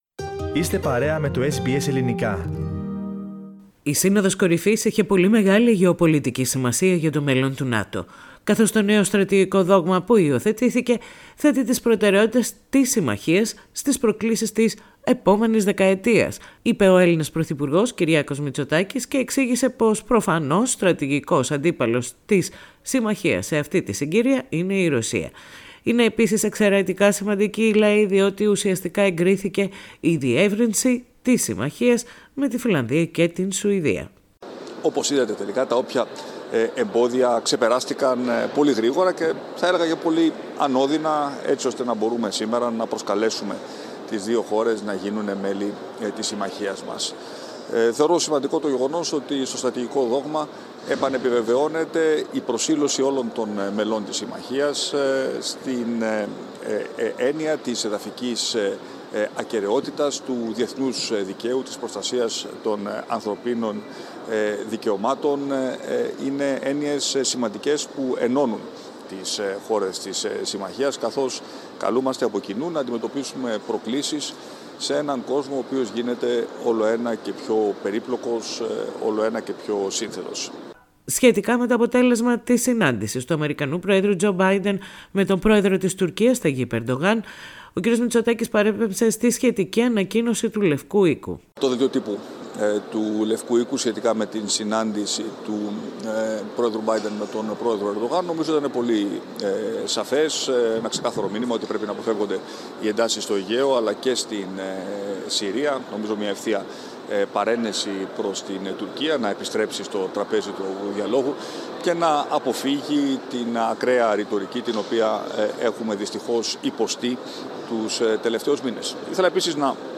Greek Prime Minister Kiriakos Mitsotakis speaks to press after the NATO Summit, Madrid, June 2022.